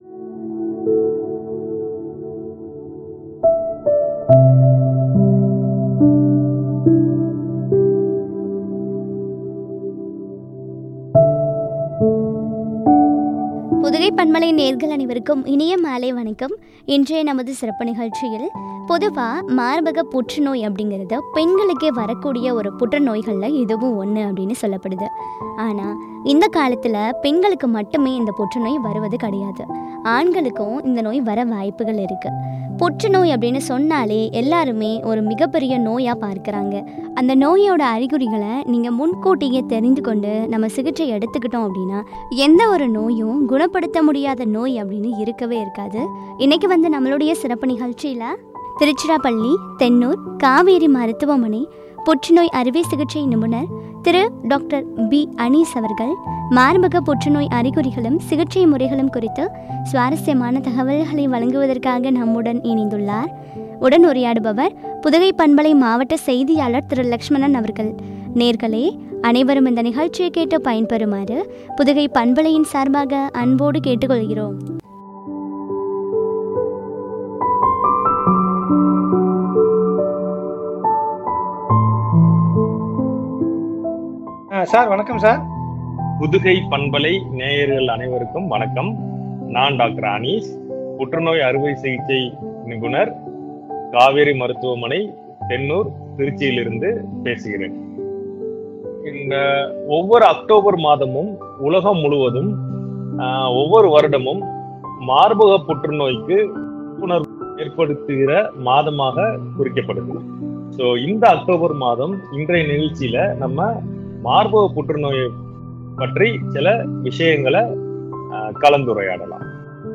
சிகிச்சை முறைகளும்” பற்றிய உரையாடல்.